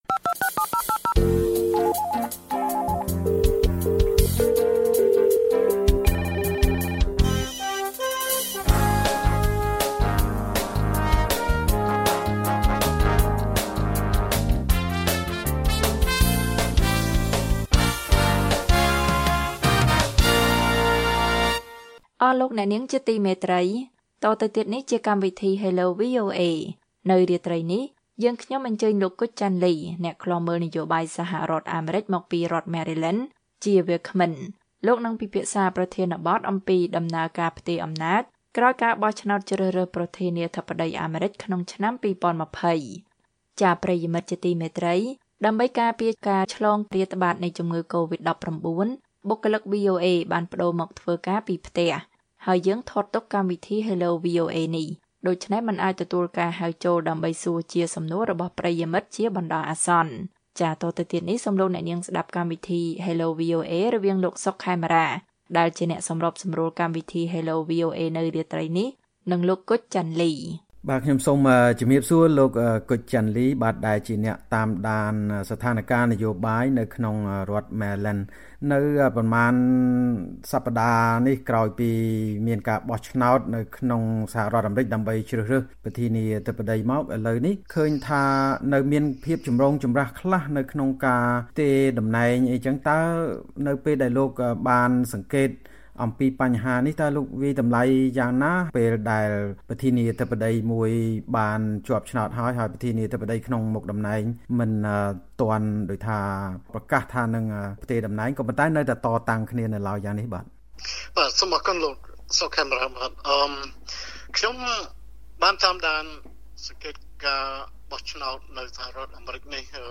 តាមទូរស័ព្ទក្នុងកម្មវិធី Hello VOA